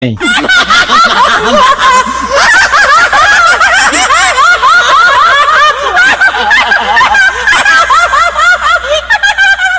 Play, download and share garras risada original sound button!!!!
garras-risada.mp3